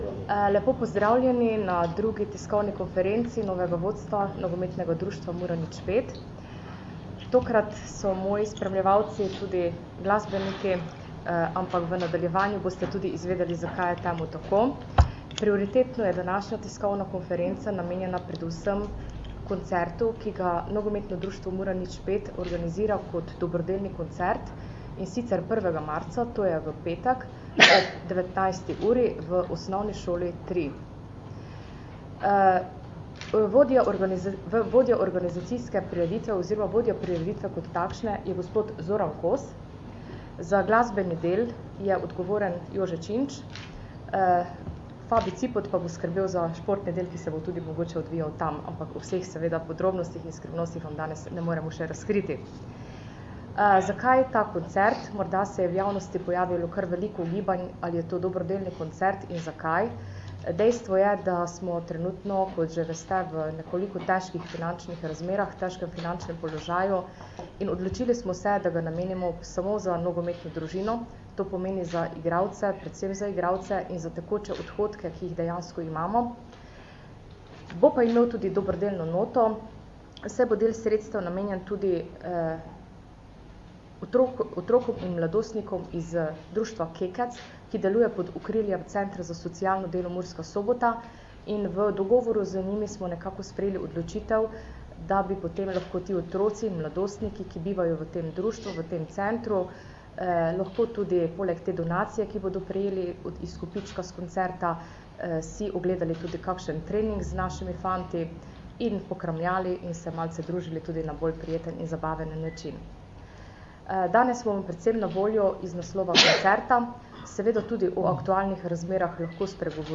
Mura bo jutri ob 15. uri na Mad�arskem imela generalko pred pri�etkom prvenstva, saj se bo pomerila s tamkaj�njim prvoliga�em Pesc-om. Audio posnetek novinarske konference.